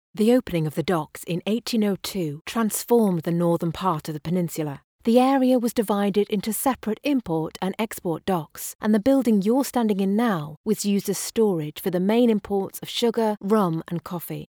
Natural, Versátil, Amable
Audioguía
She has a broadcast quality home studio and is a popular choice amongst clients.